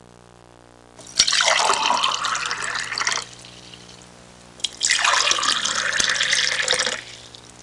Filling A Glass Sound Effect
Download a high-quality filling a glass sound effect.
filling-a-glass-1.mp3